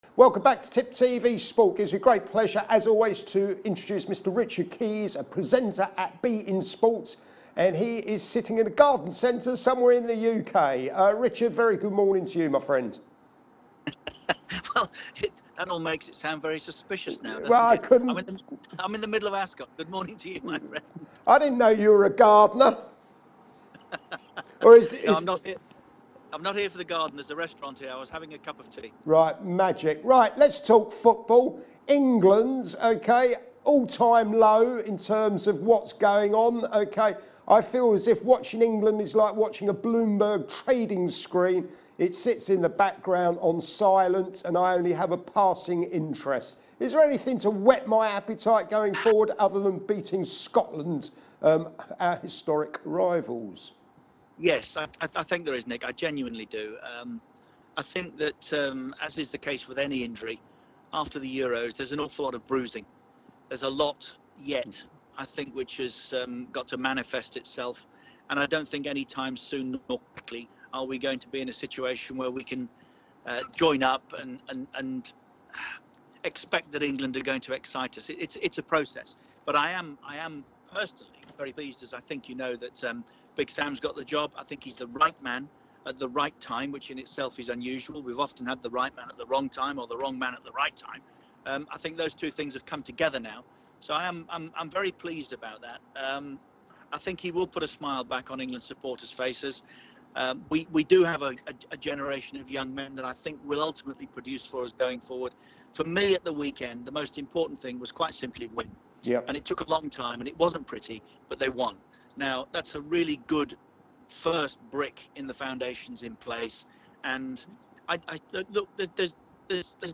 We spoke to Richard Keys to talk football following England's last minute win against Slovakia yesterday evening. After the poor performance of the national team at the Euro's, Keys is delighted that Sam Allardyce has been appointed and looks forward to him 'putting a smile back on England supporters faces'.